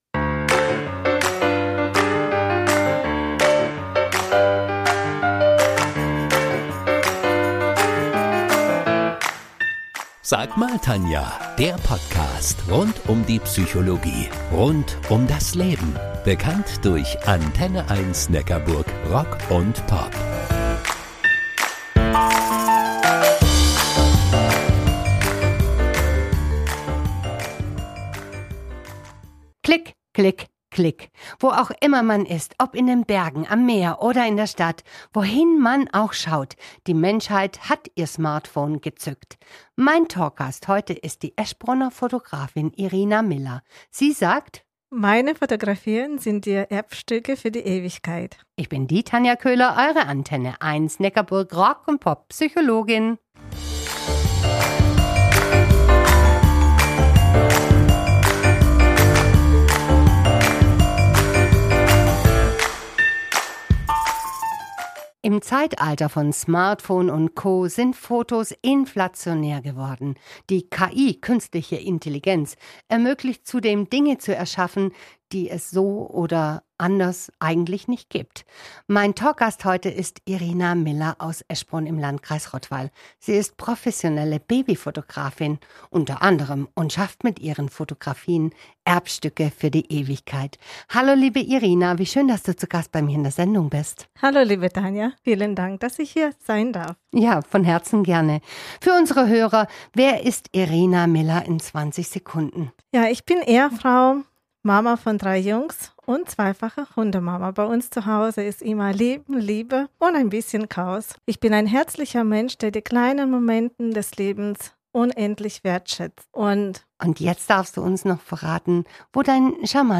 Im Gespräch
Diese Podcast-Episode ist ein Mitschnitt der Original-Redebeiträge